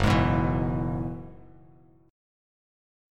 F#9sus4 chord